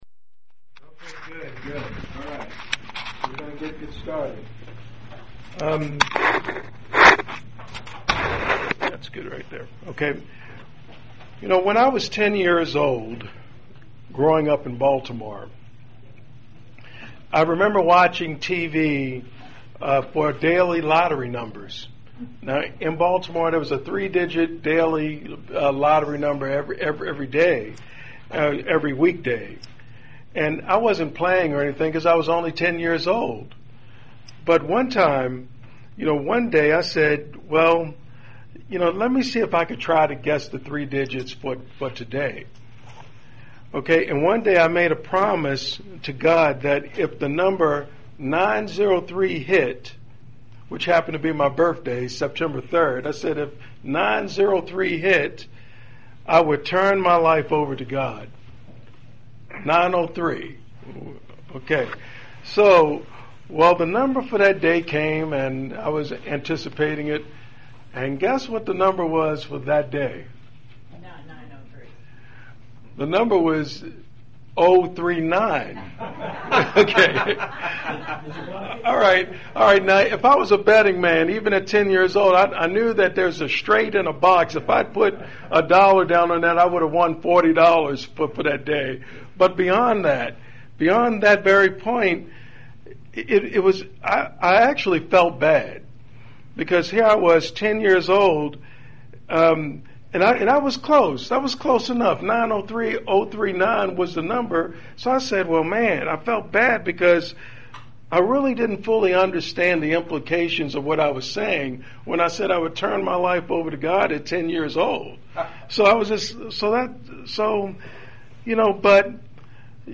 This sermon contains five points about keeping our word: 1.
Given in Bowling Green, KY